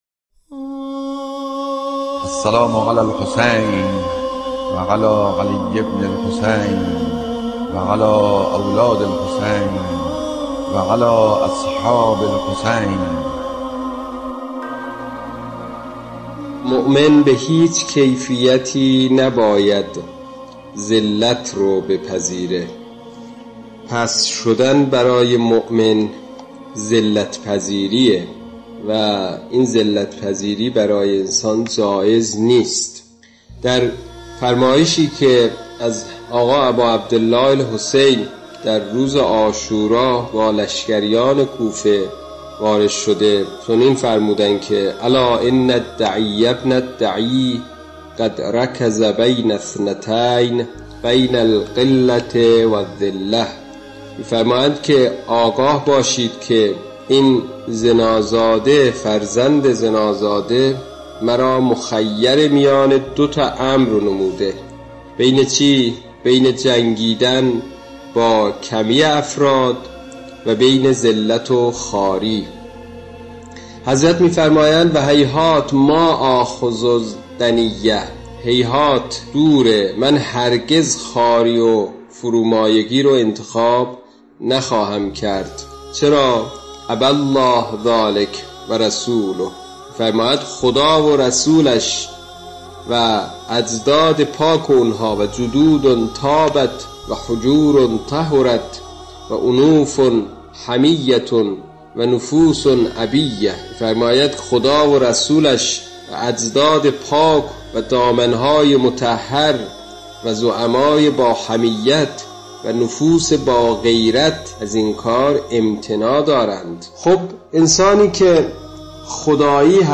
پادپخش بیانات